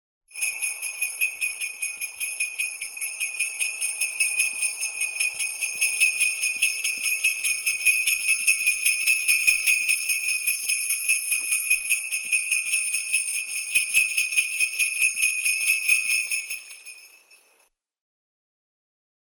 Здесь вы можете слушать онлайн и скачать бесплатно чистые, серебристые перезвоны, которые ассоциируются с Рождеством, зимней сказкой и санями Деда Мороза.
Новогодний звук приближающегося и удаляющегося звона бубенцов